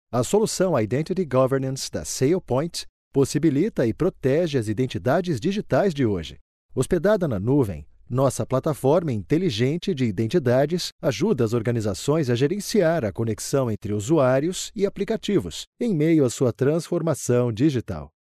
Vídeos corporativos
Mi tono es conversacional, pero también puedo hacer la voz de un adulto joven y de una persona mayor.